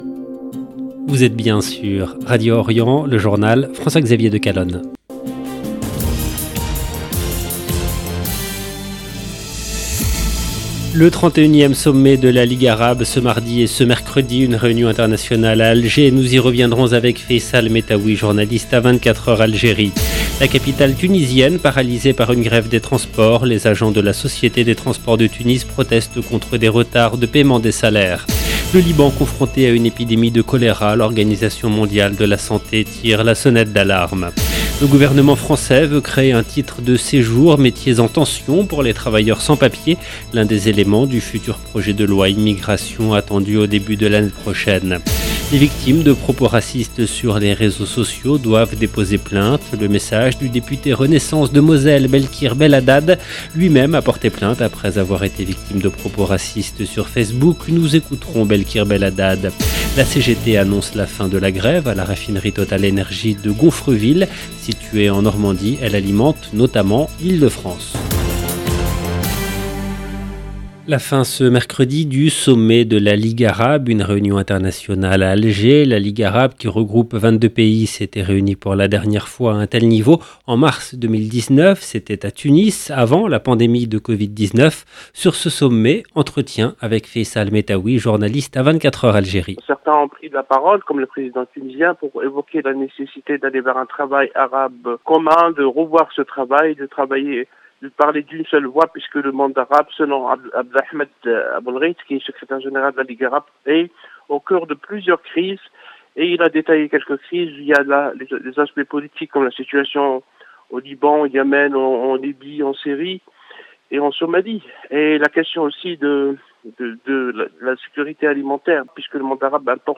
EDITION DU JOURNAL DU SOIR EN LANGUE FRANCAISE DU 2/11/2022